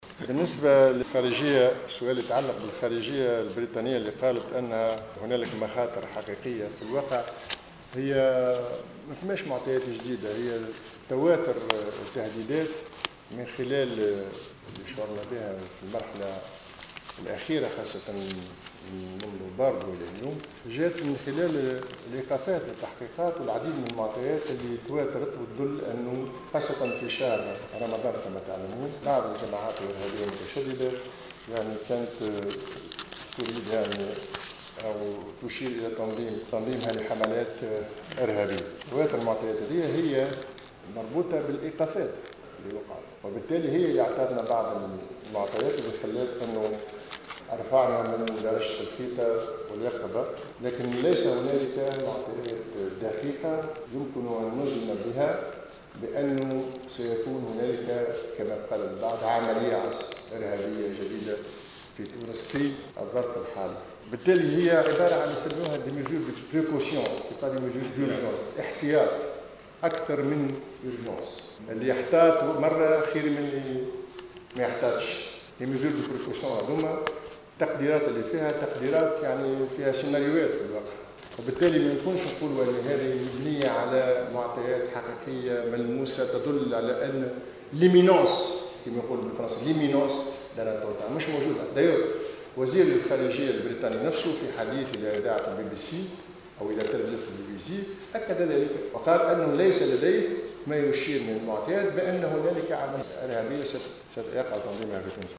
خلال ندوة صحفية عقدت اليوم الجمعة 10 جوان 2015